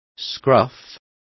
Complete with pronunciation of the translation of scruff.